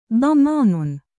音标: /ˈɖamaːn/